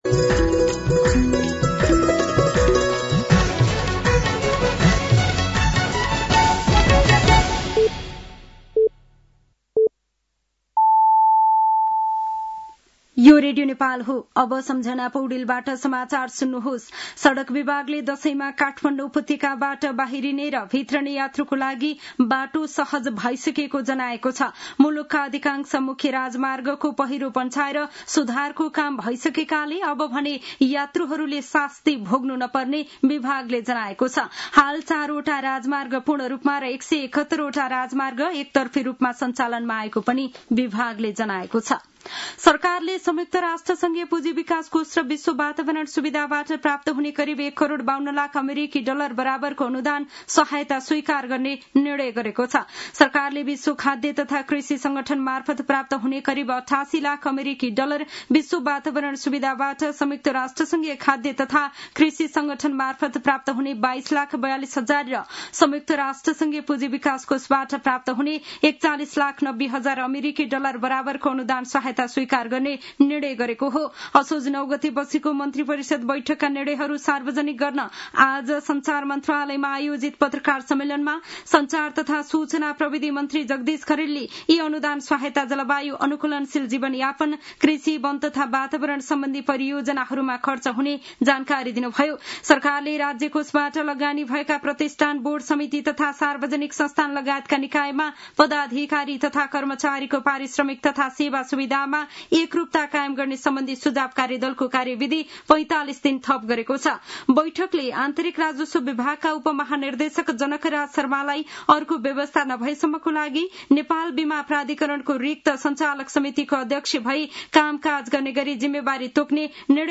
साँझ ५ बजेको नेपाली समाचार : १० असोज , २०८२